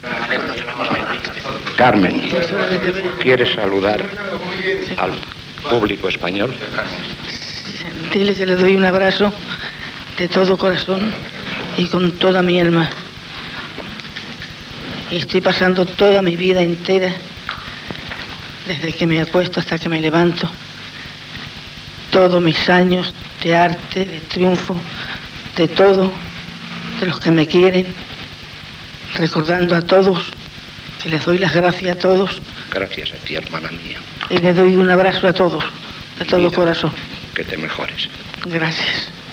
Última entrevista a la ballarina flamenca Carmen Amaya, ingressada a la Clínica Puigvert de Barcelona.
Informatiu